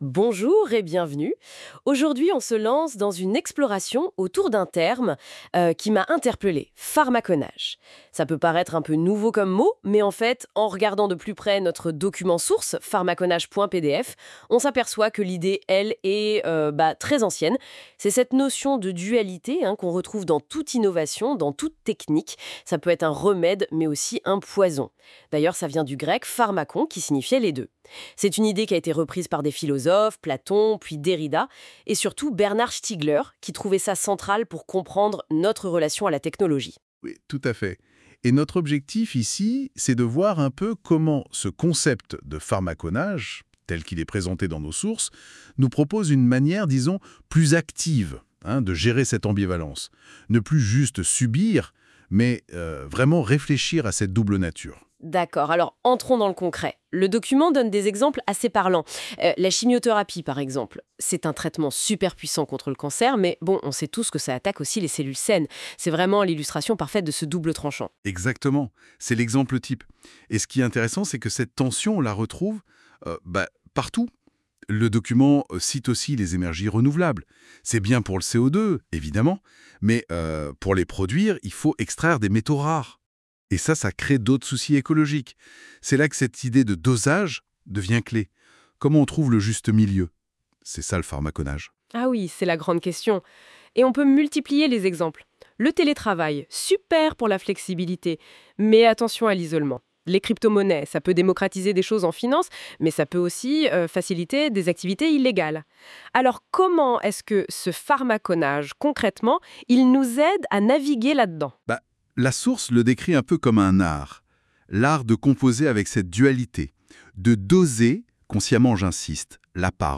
Écouter le billet raconté par deux personnages virtuels grâce à Notebooklm :